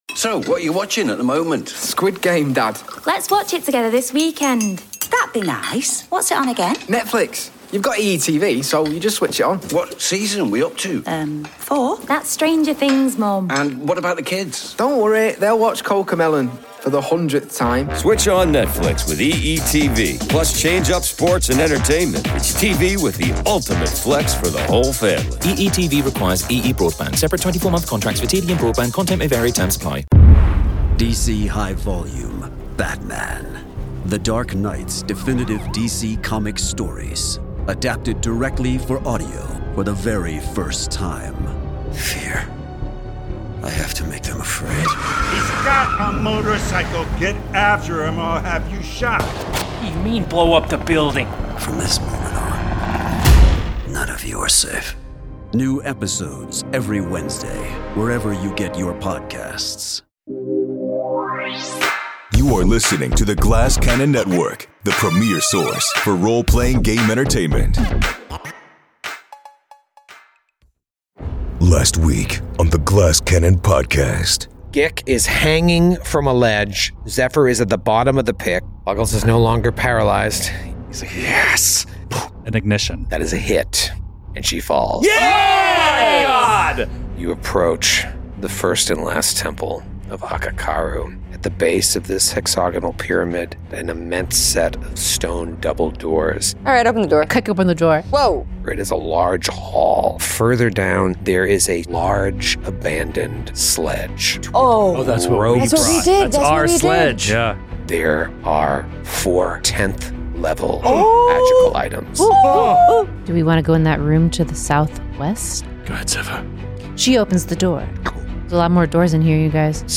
In the spirit of old-school tabletop role-playing games, a collection of five super-nerds engage in an officially licensed Actual Play podcast of Paizo’s Giantslayer Adventure Path!